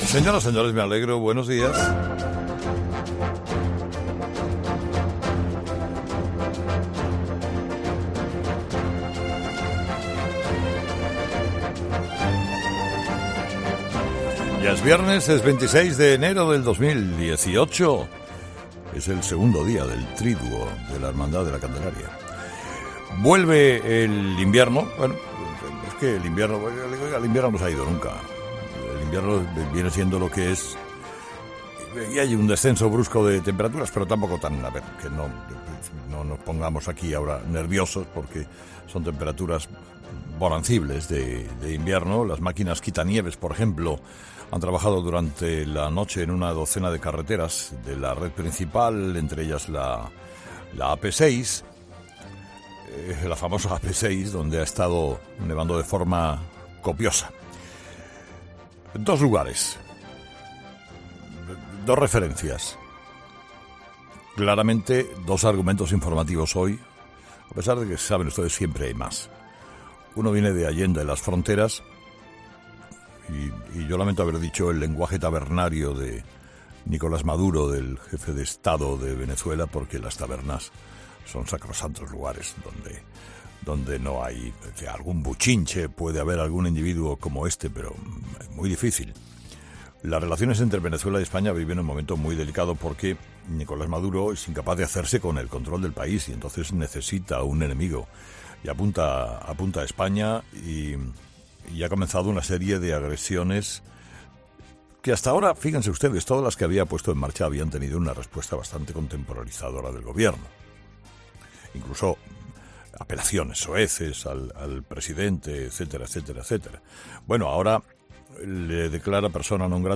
AUDIO: El monólogo de Carlos Herrera en 'Herrera en COPE' con todo el análisis de la actualidad